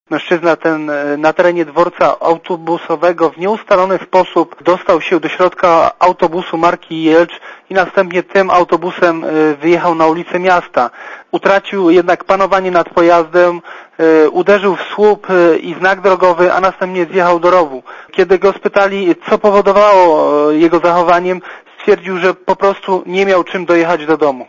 Mówi